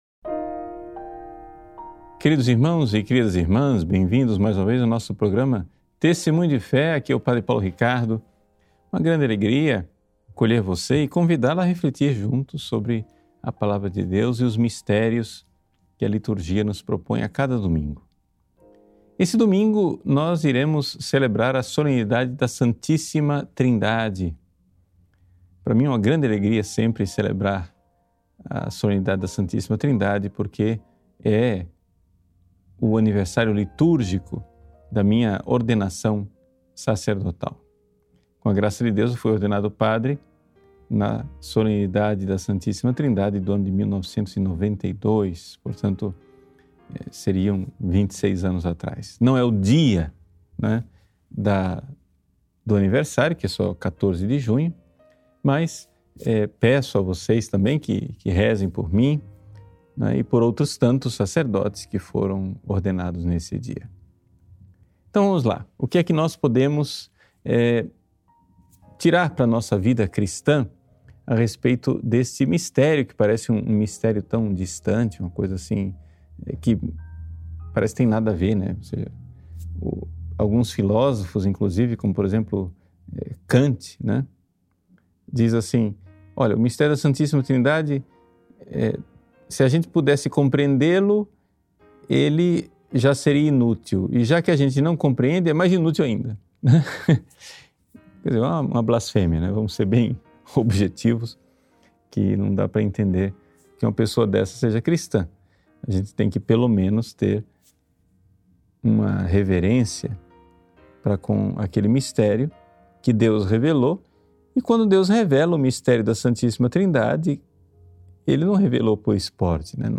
Material para Download Áudio da homilia (Formato .mp3) O que achou desse conteúdo?